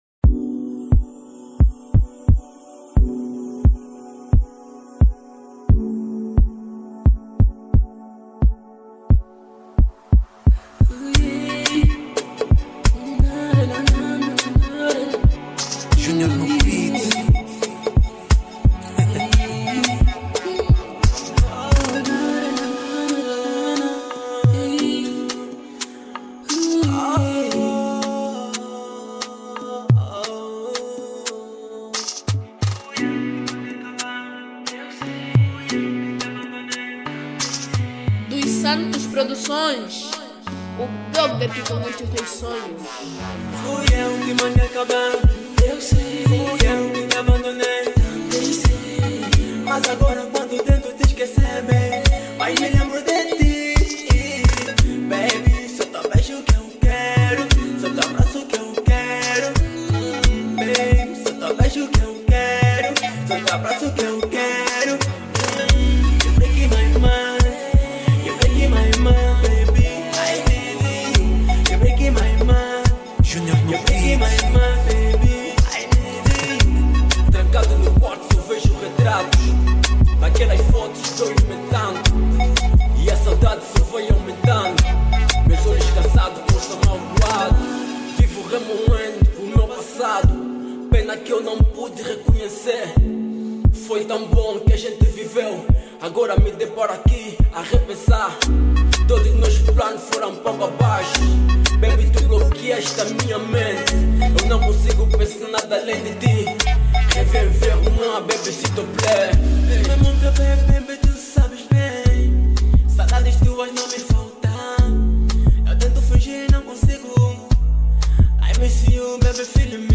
Categoria: Zouk